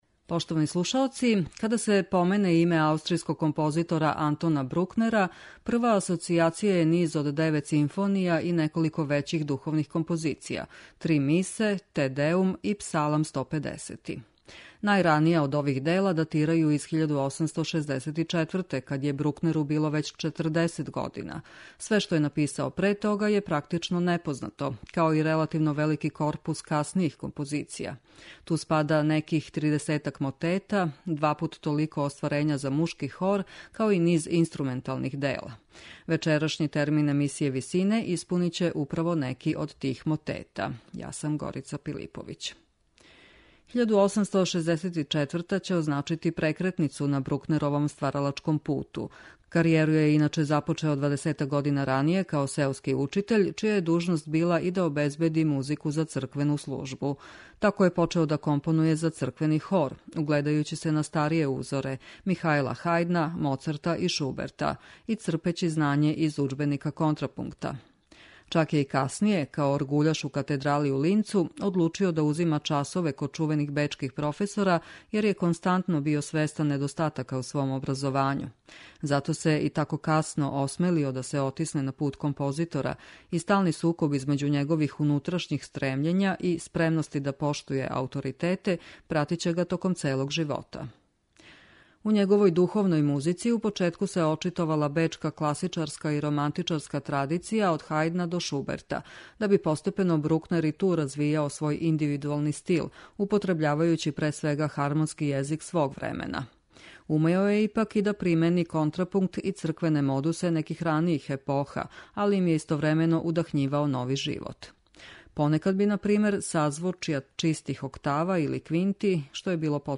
Мотети Антона Брукнера